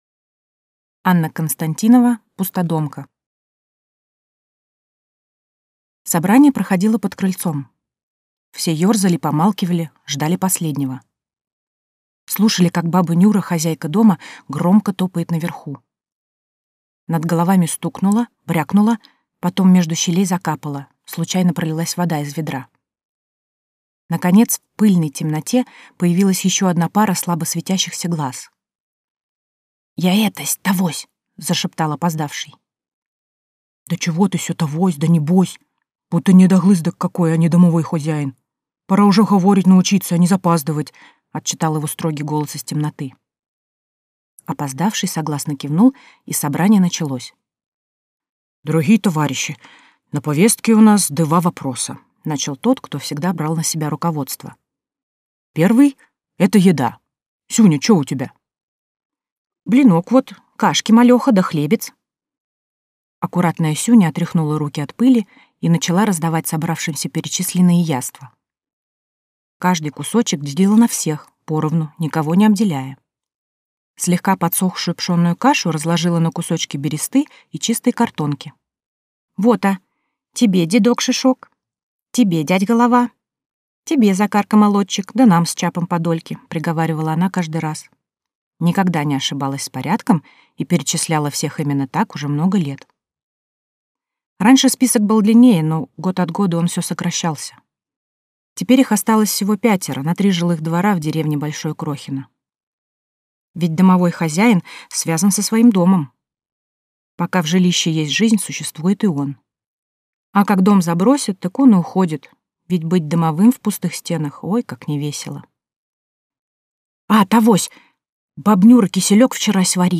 Аудиокнига Пустодомка | Библиотека аудиокниг